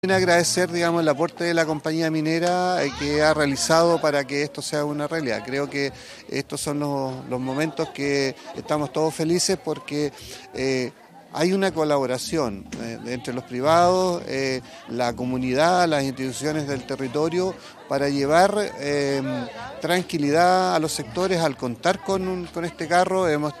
AUDIO : Bernardo Leyton – Alcalde de Canela